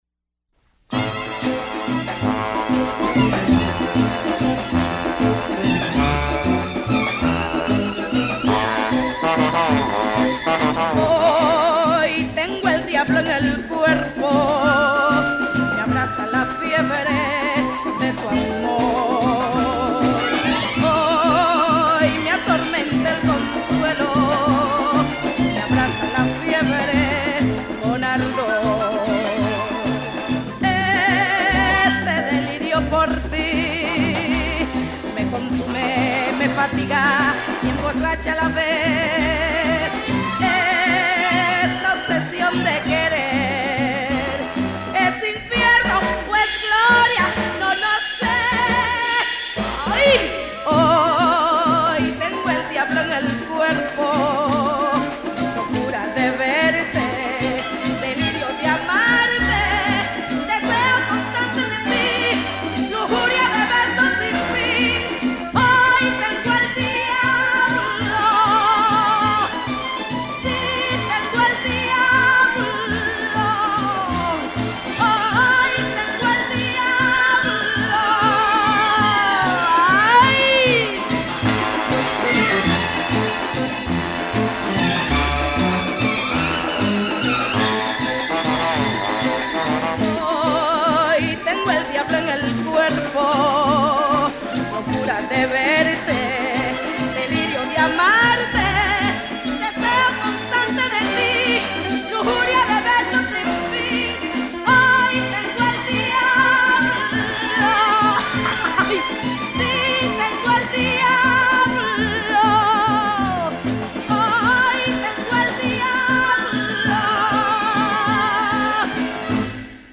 calypso
grabado en La Habana.